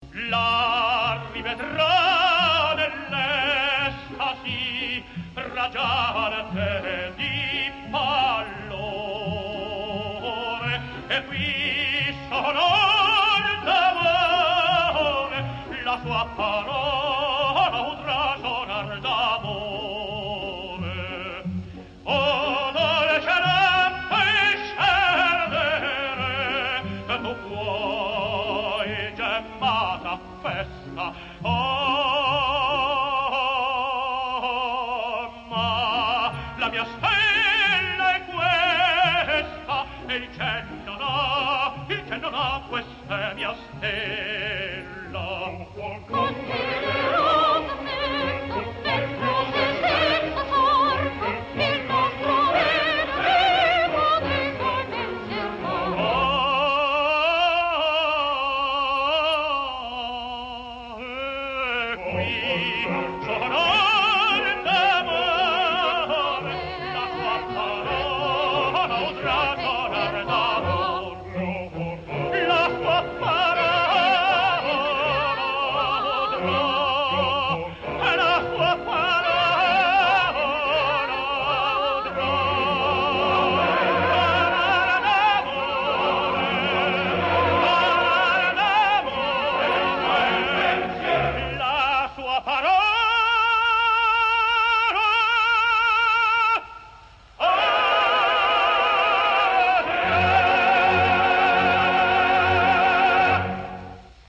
opera completa, registrazione in studio.
Coro